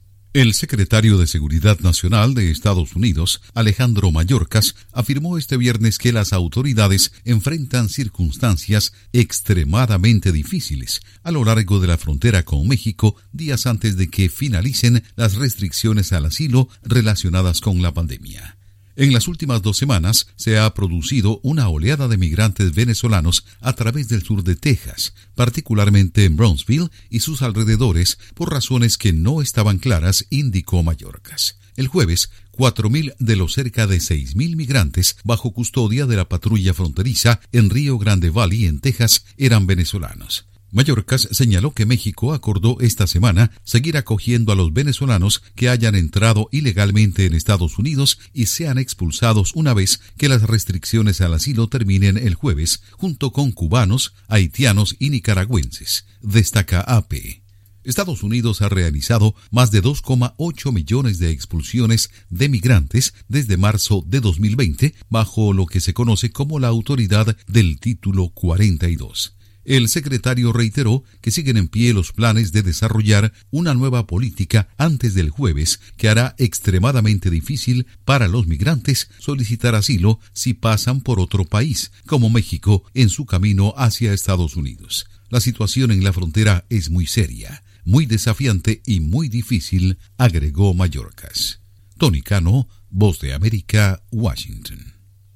Mayorkas: Frontera es “muy desafiante” en estos momentos. Informa desde la Voz de América en Washington